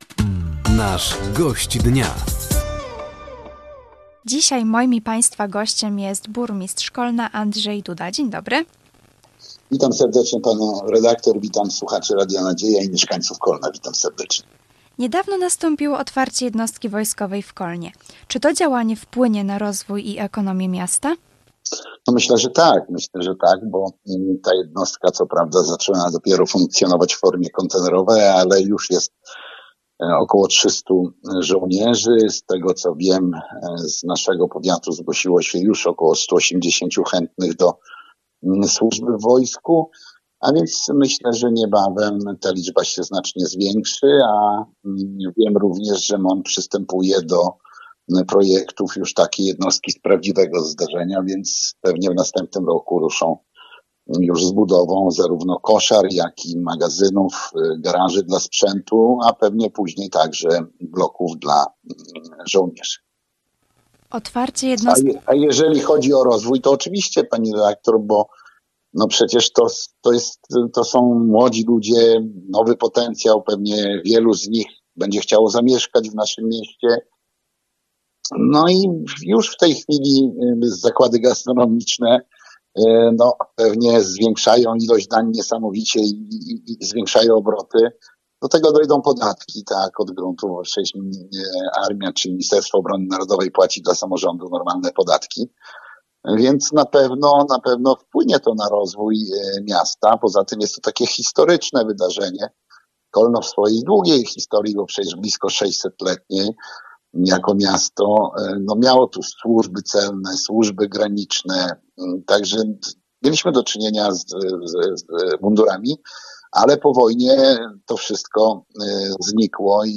Gościem Dnia Radia Nadzieja był dzisiaj Burmistrz Kolna, Andrzej Duda. Tematem rozmowy było otwarcie jednostki wojskowej w Kolnie, wydarzenia wakacyjne oraz budowa stadionu miejskiego.